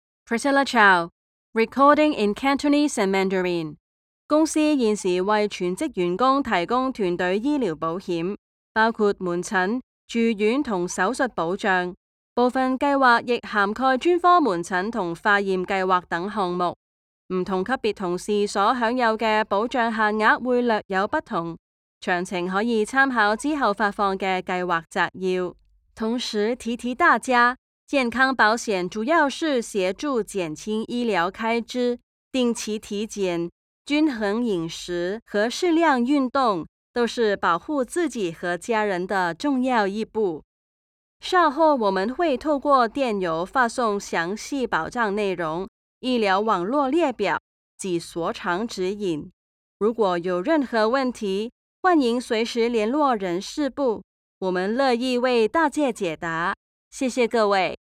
Foreign & British Female Voice Over Artists & Actors
Child (0-12) | Adult (30-50)